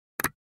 click.mp3